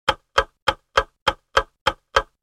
جلوه های صوتی
دانلود صدای ساعت 20 از ساعد نیوز با لینک مستقیم و کیفیت بالا
برچسب: دانلود آهنگ های افکت صوتی اشیاء دانلود آلبوم صدای ساعت از افکت صوتی اشیاء